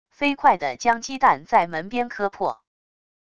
飞快的将鸡蛋在门边磕破wav音频